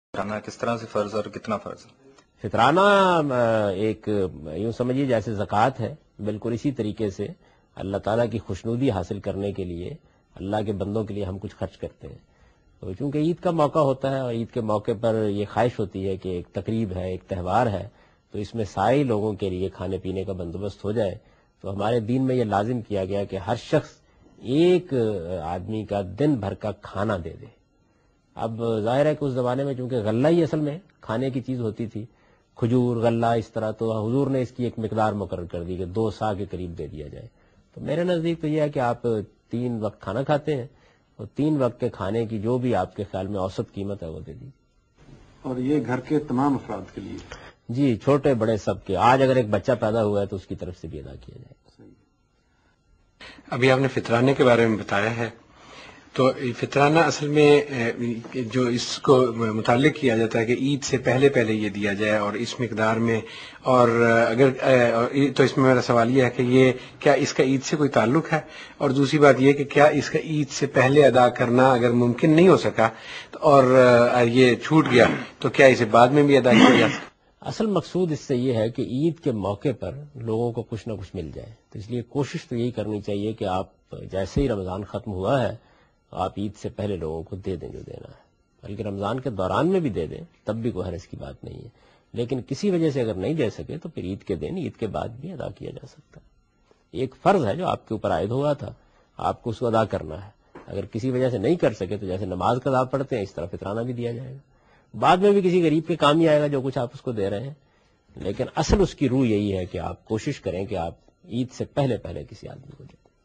Category: Reflections / Questions_Answers /
Javed Ahmed Ghamidi answering a question regarding Fitrana.